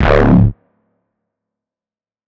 Sickï Bass.wav